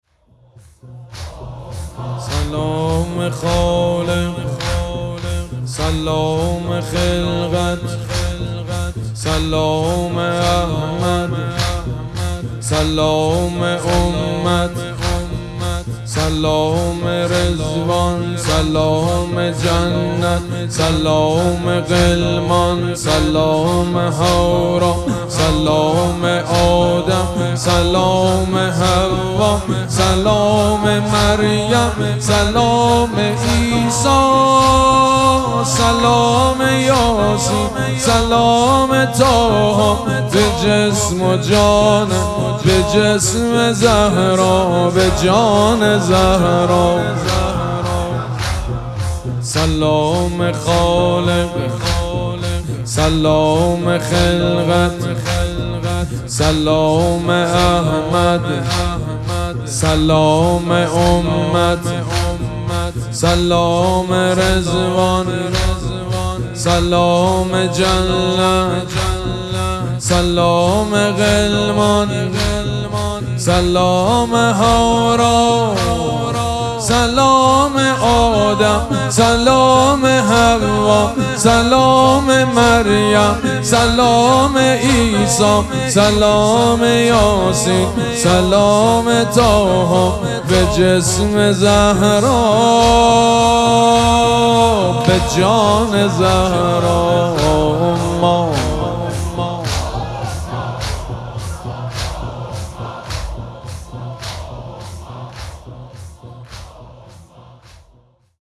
شب پنجم مراسم عزاداری دهه دوم فاطمیه ۱۴۴۶
حسینیه ریحانه الحسین سلام الله علیها
مداح
حاج سید مجید بنی فاطمه